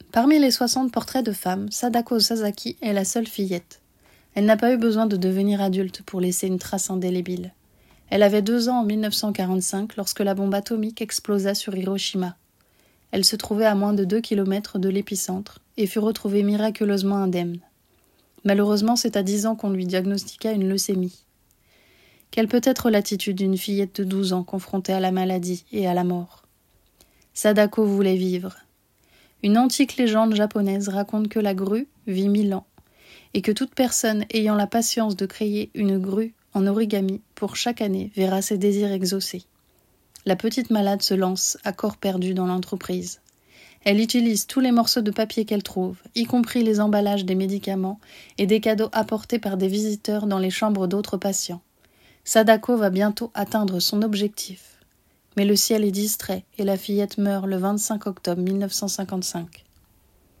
Voix / voice
27 - 49 ans - Mezzo-soprano